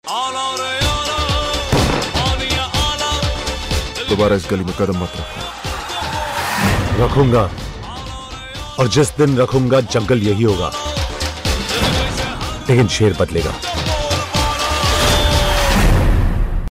Dialogue Tone